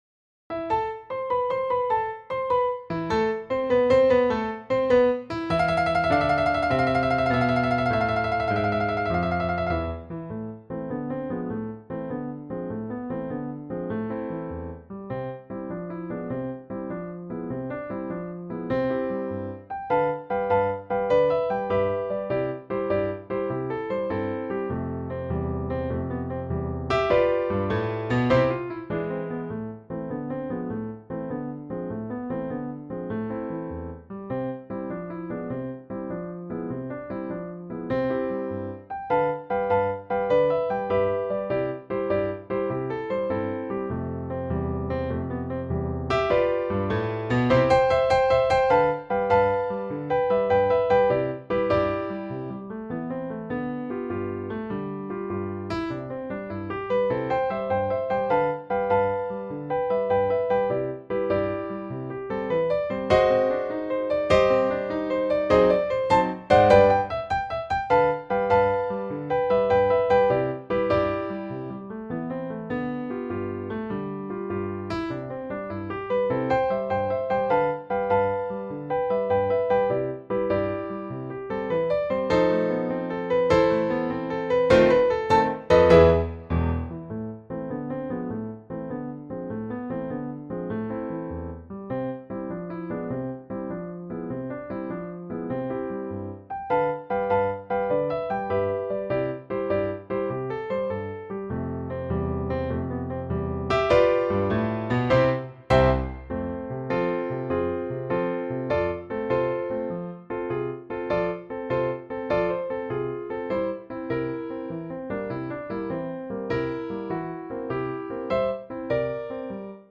for Piano Solo